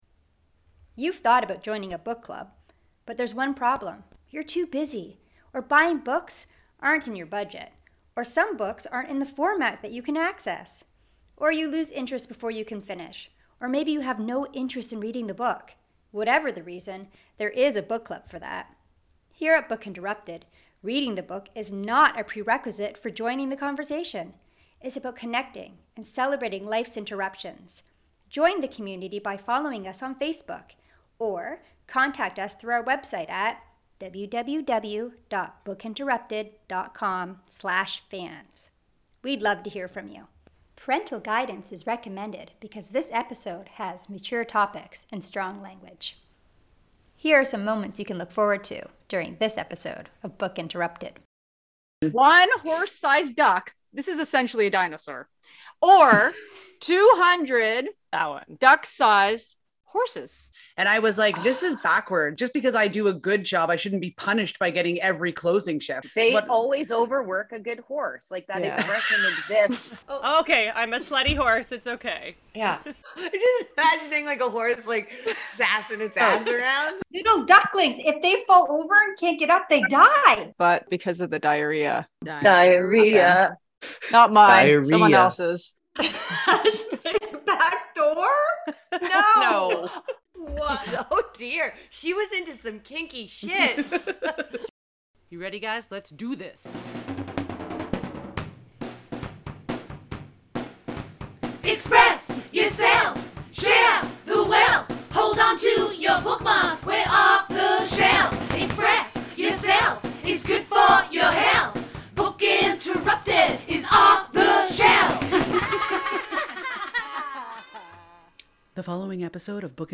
During this episode we take a glimpse behind the scenes while the Book Interrupted women discuss being a work horse, embodying the character of a slutty horse, kicking horses, dinosaur ducks, duck armies and diarrhoea.